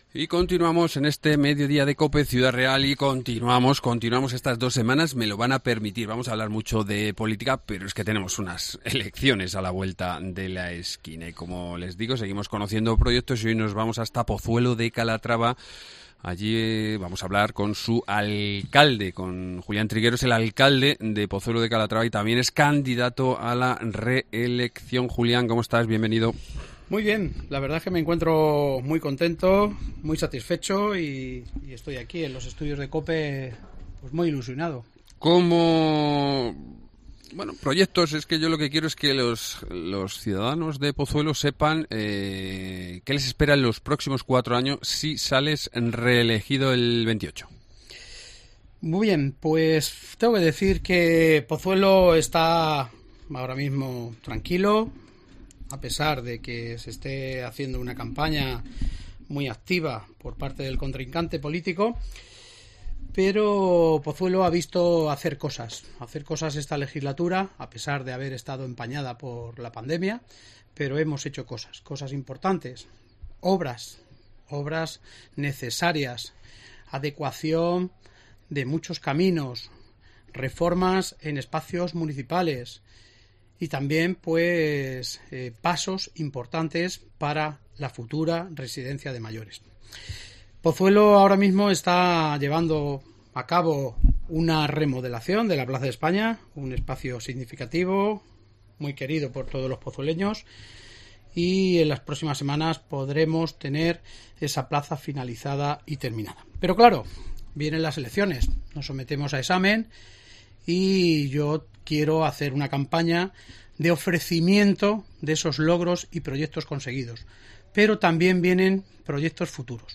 Entrevista
Julián Triguero, alcalde de Pozuelo de Calatrava y candidato a la reelección